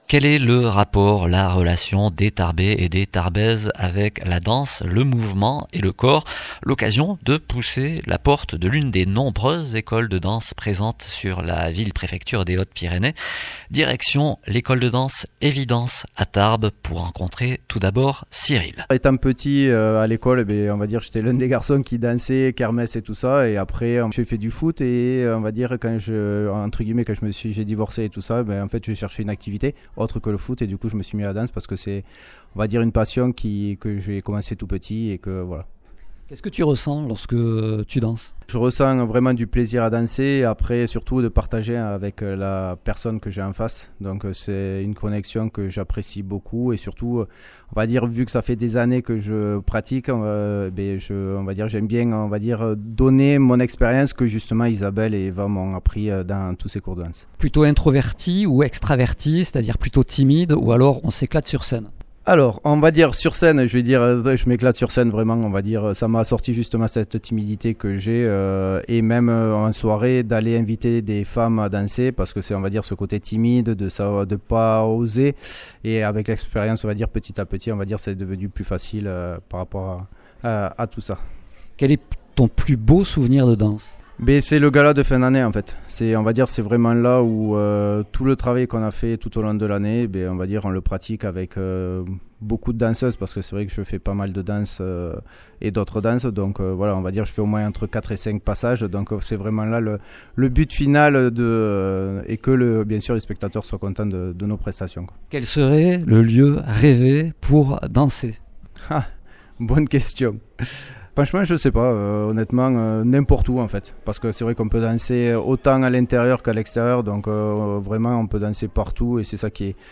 Un reportage qui explore le lien intime que des danseurs amateurs tarbais entretiennent avec le mouvement, entre expression personnelle et plaisir du geste. À travers leurs récits, la danse apparaît comme un espace de liberté, de partage et d’émotion.